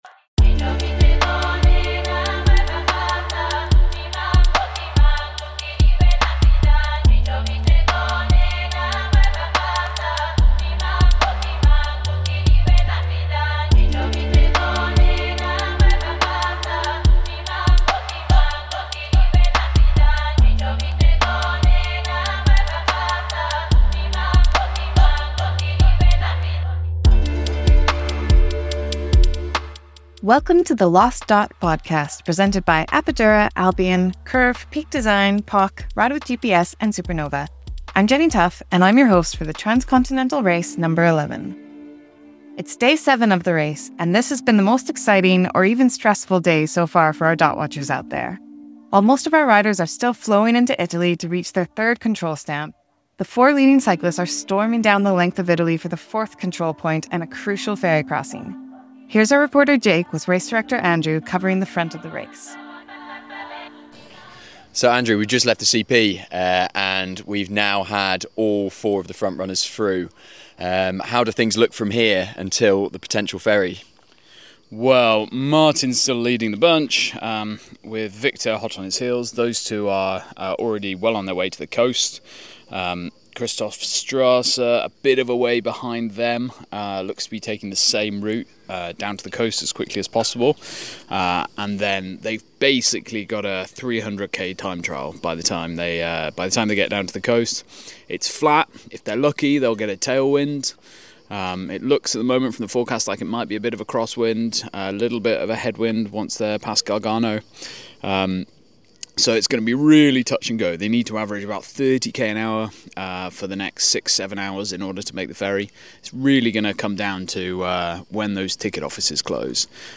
TCRNo11 // Day 07 Aug 04, 2025, 03:39 PM Headliner Embed Embed code See more options Share Facebook X Subscribe It’s day 07 of the Transcontinental Race, and the four leading riders are in a close race to the ferry, a strategic bottleneck in this year’s race. Control Team 1 waits at the ferry port in Bari to see who will make the night’s crossing.